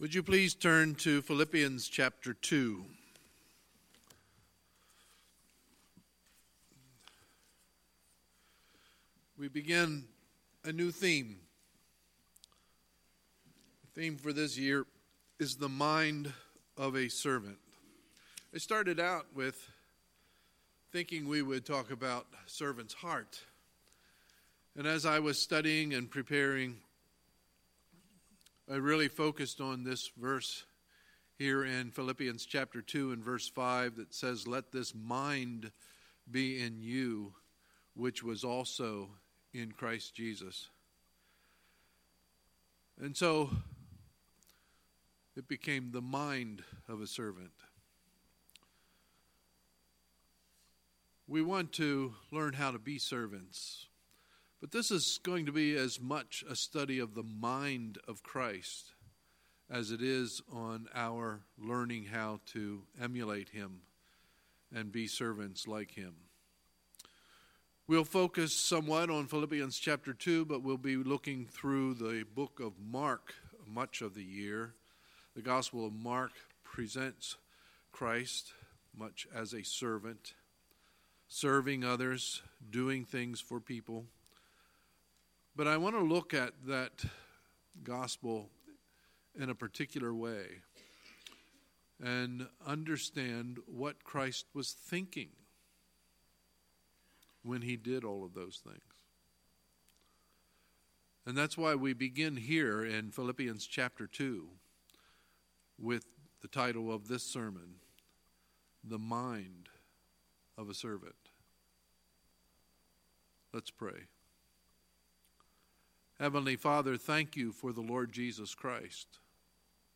Sunday, January 6, 2019 – Sunday Morning Service